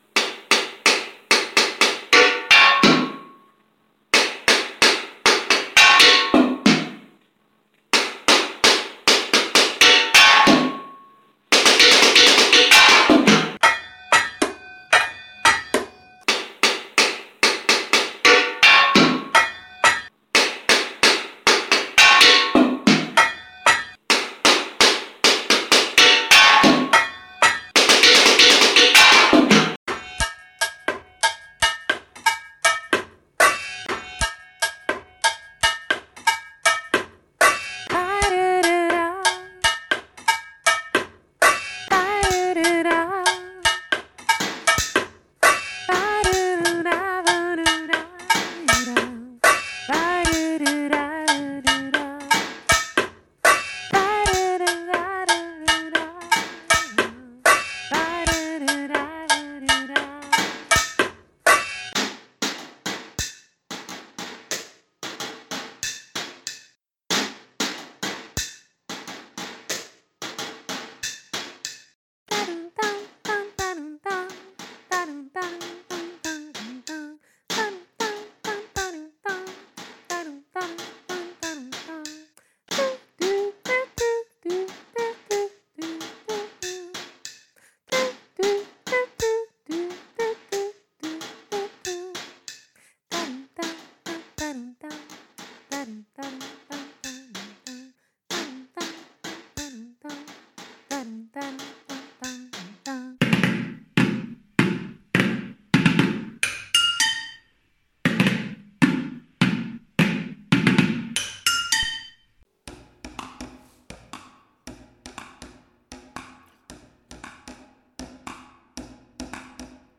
Batterie remix è un esperimento compositivo di I Grand Tour nato dalla costruzione di batterie artigianali da parte degli alunni che hanno riprodotto alcuni ritmi studiati insieme e ne hanno composti degli altri
batterie_remix_1gt.mp3